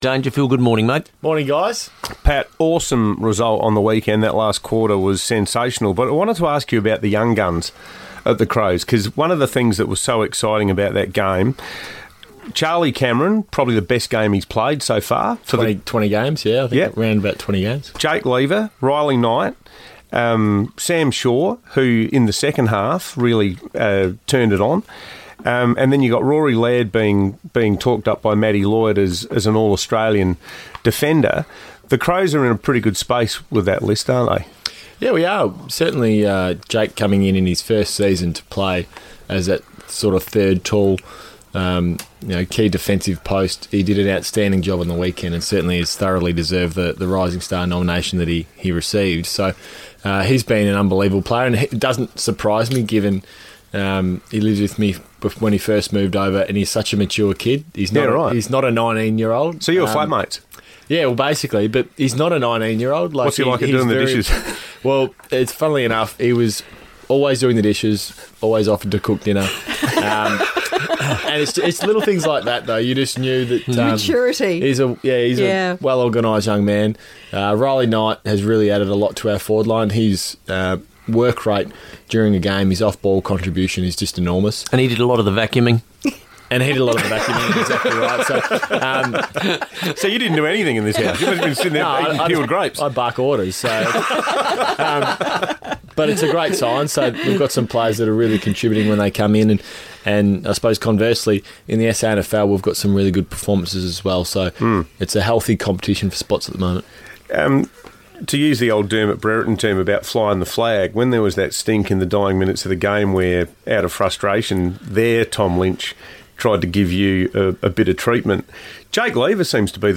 In-form midfielder Patrick Dangerfield spoke on his regular breakfast spot on FIVEaa